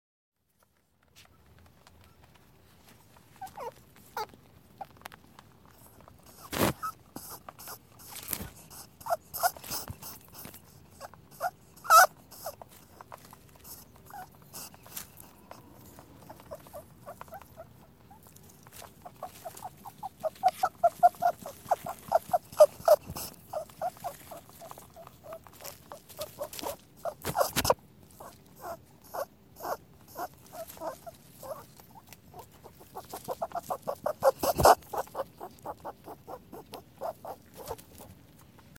Rabbit Oinks And Squeaks Botón de Sonido
Animal Sounds Soundboard291 views